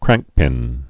(krăngkpĭn)